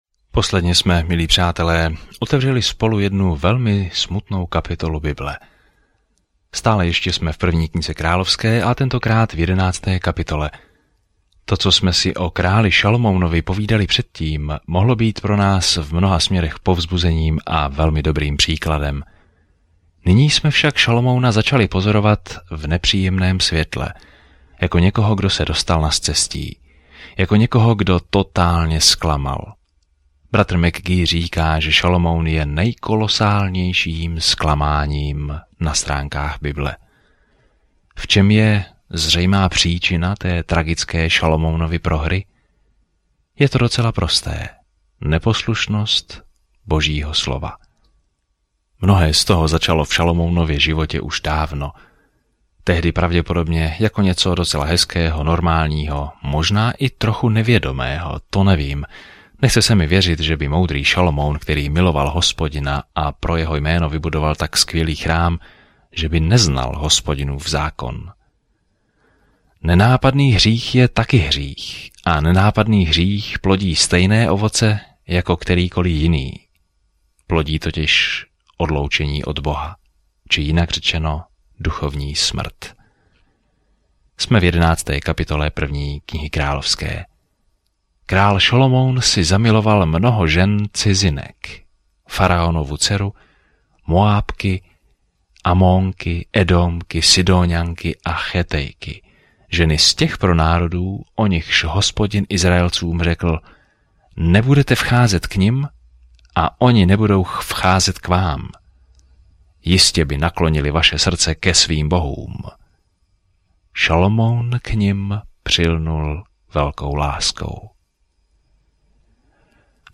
Písmo 1 Královská 11:9-43 Den 12 Začít tento plán Den 14 O tomto plánu Kniha králů pokračuje v příběhu o tom, jak izraelské království za Davida a Šalomouna vzkvétalo, ale nakonec se rozpadlo. Denně cestujte po 1. králi, poslouchejte audiostudii a čtěte vybrané verše z Božího slova.